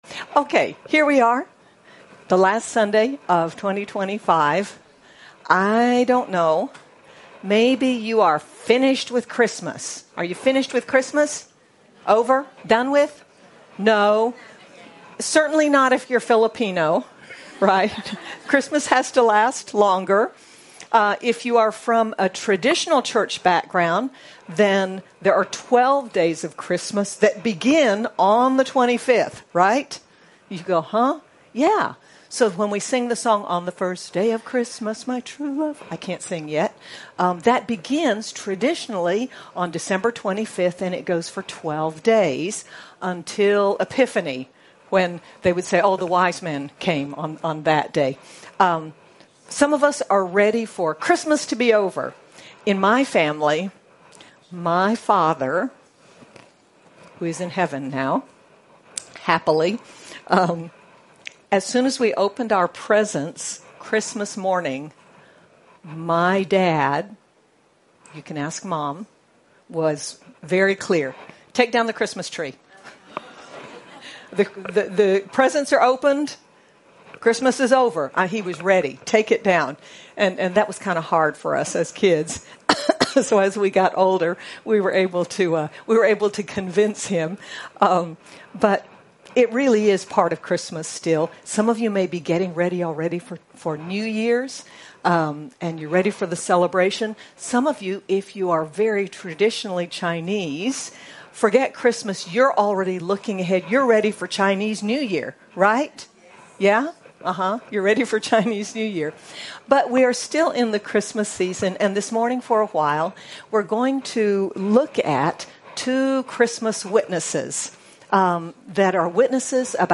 Two old, faithful witnesses, Simeon and Anna, are examples for us to follow. Sermon by